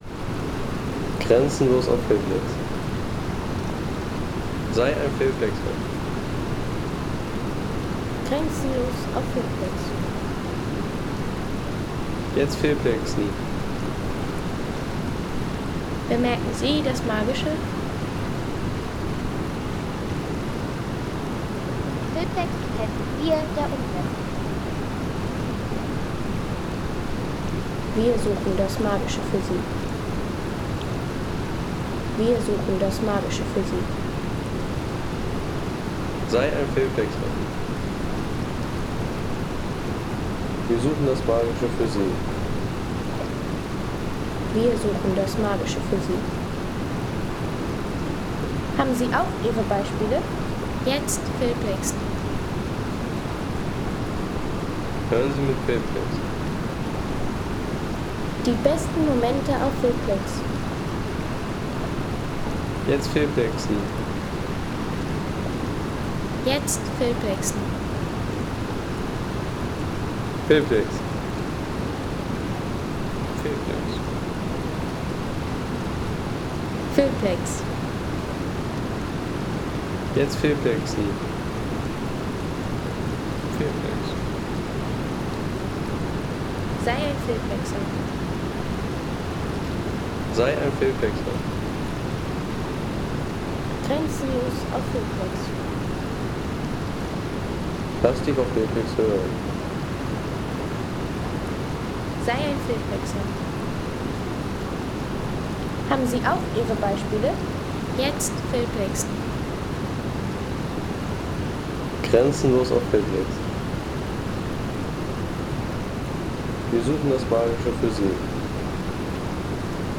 Landschaft - Bäche/Seen